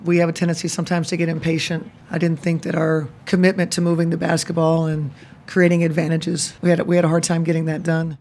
Lynx head coach Cheryl Reeve says the offense sputtered too much in this one.